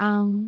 speech
syllable
pronunciation
aang3.wav